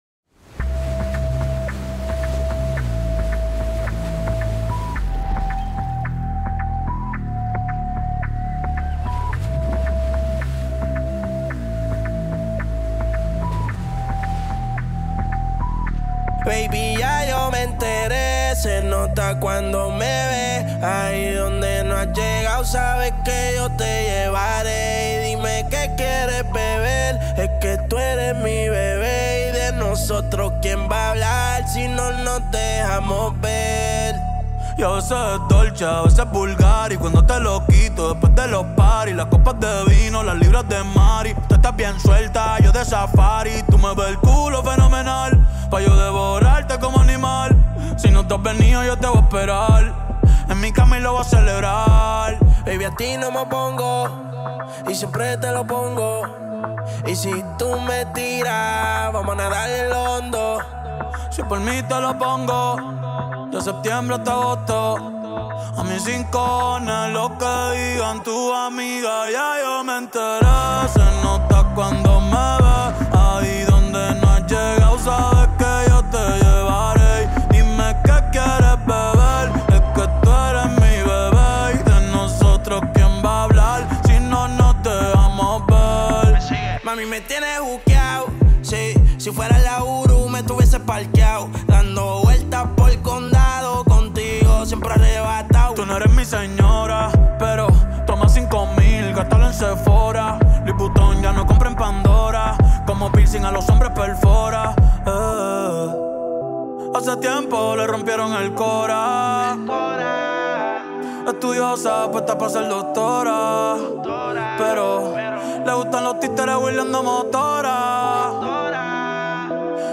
Latin Trap Global Hit Night Vibes